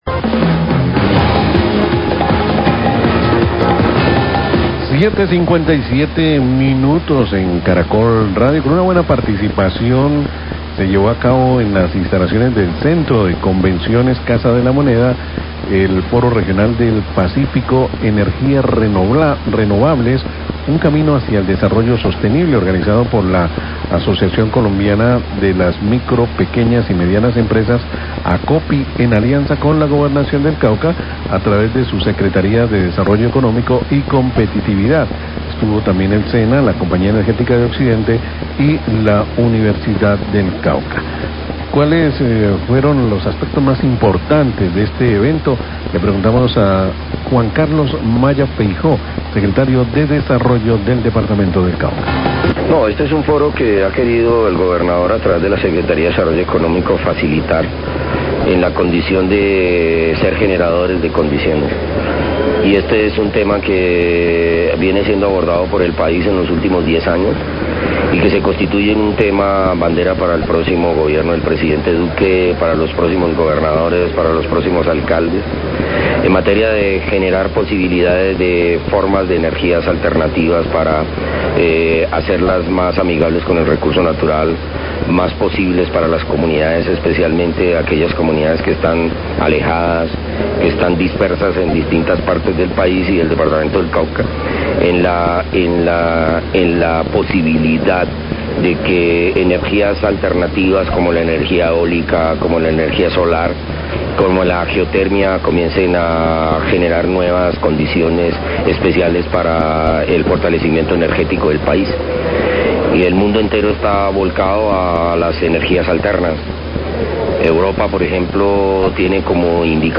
Radio
Con buena participación se llevó a cabo en el Centro de Convenciones Casa de la Moneda el 'Foro Regional del Pacífico, Energías Renovables, Un camino hacia el desarrollo sostenible', realizado por Acopi en alianza con la Gobernación del Cauca, Sena, Compañía Energética, Unicauca. Declaraciones de Juan Carlos maya, Secretario de Desarrollo del Cauca.